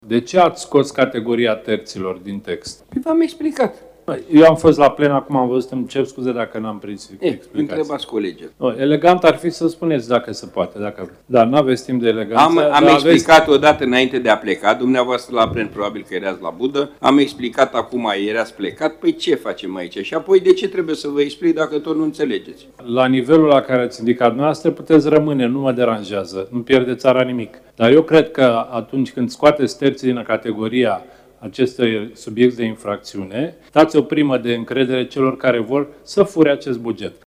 Exluderea terților dintre beneficiarii abuzului în serviciu a dat naștere unui dialog neplăcut între autorul modificării abuzului, deputatul PSD Eugen Nicolicea și fostul ministru al Justiției, Cătălin Predoiu.
02iul-16-cearta-Nicolicea-Predoiu-.mp3